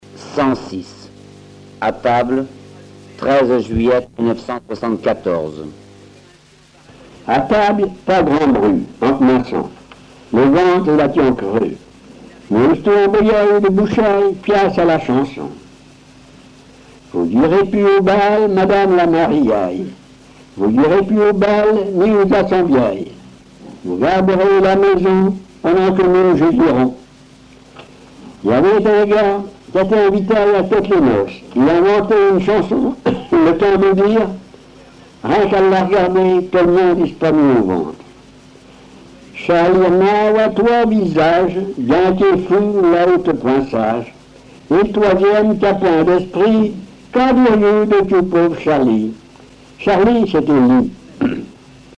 Genre récit
Récits en patois